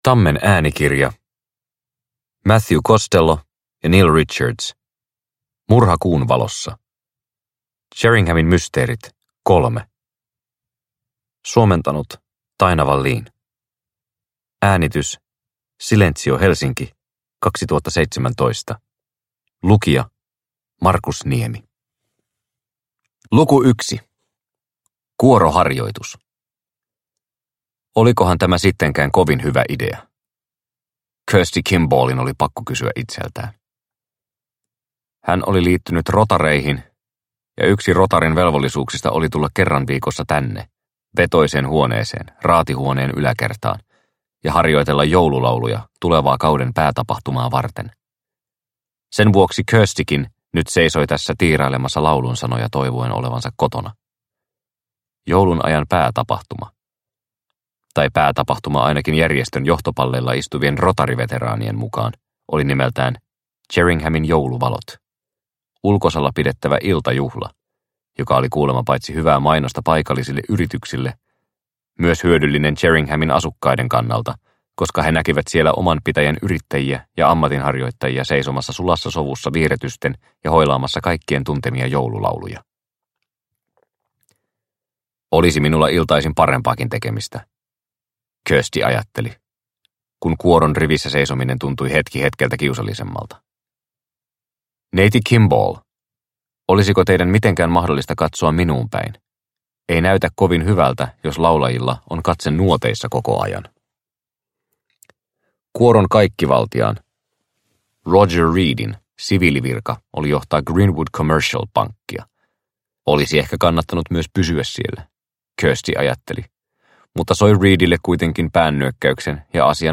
Murha kuunvalossa – Ljudbok – Laddas ner